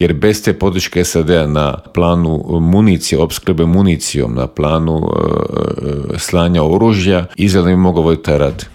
Bivšeg ministra vanjskih i europskih poslova Miru Kovača u Intervjuu Media servisa upitali smo - je li ga iznenadio postupak SAD-a?